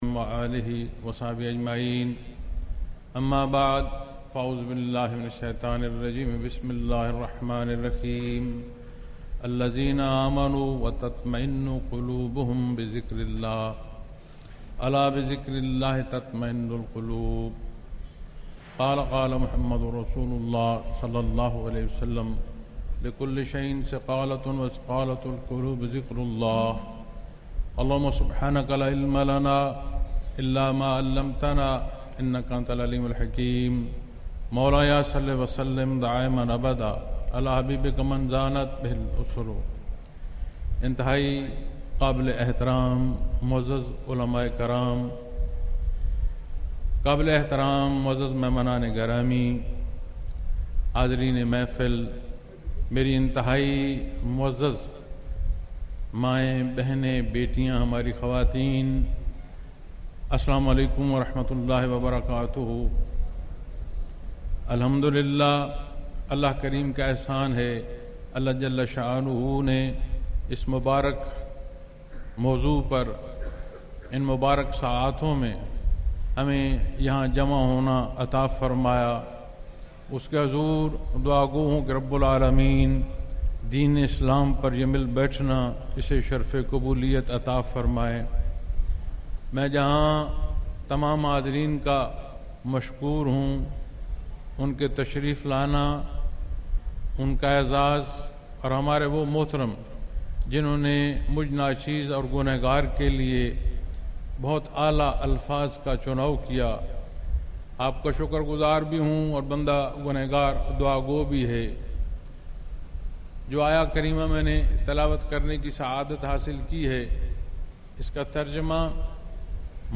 Baisat Rehmat Alam SAW Conference Lahore | Silsila Naqshbandia Owaisiah